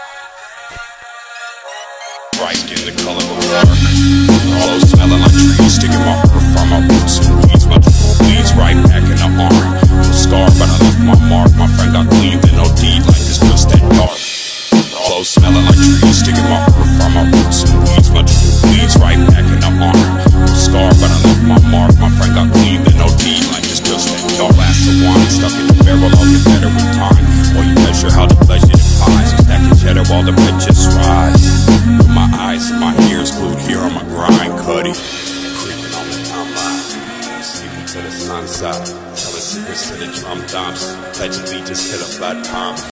• Качество: 320, Stereo
атмосферные
Trap